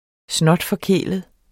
Udtale [ ˈsnʌdfʌˈkεˀləð ]